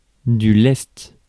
Ääntäminen
IPA: [lɛst]